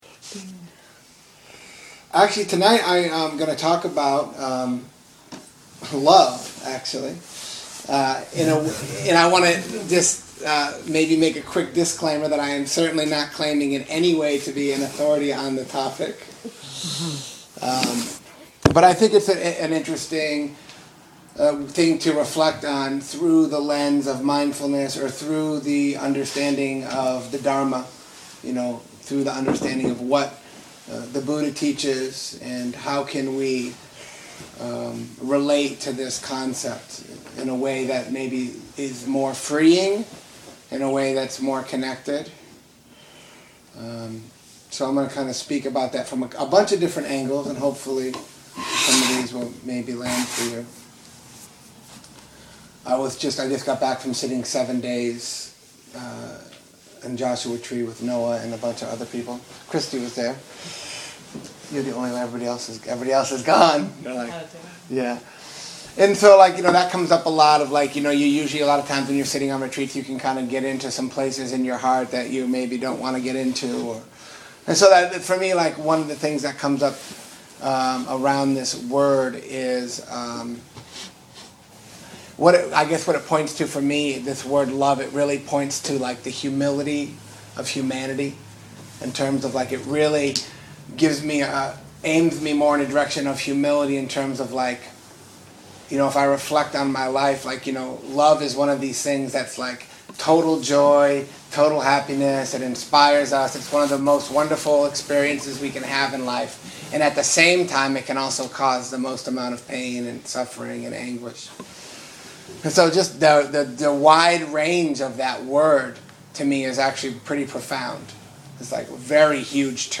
Refections on love from the vaults of talks given in Nashville, a long time ago.....in a galaxy far. far away.